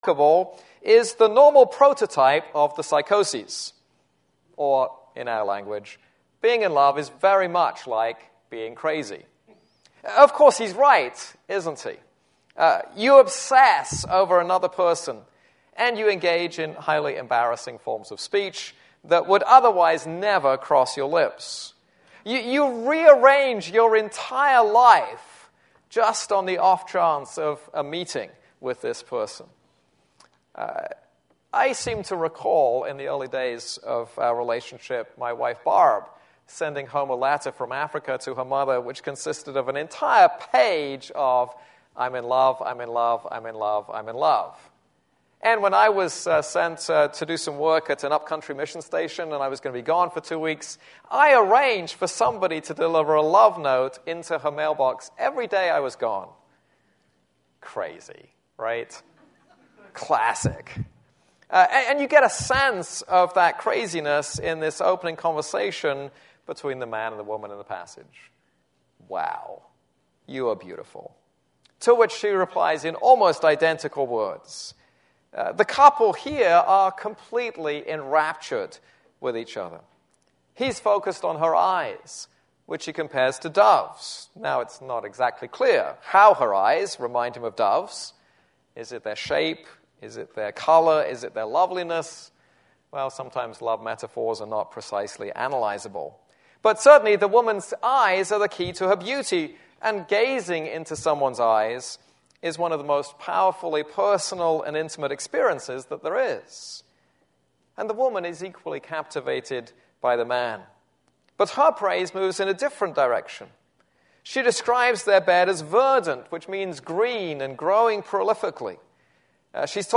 This is a sermon on Song of Songs 1:15-2:7.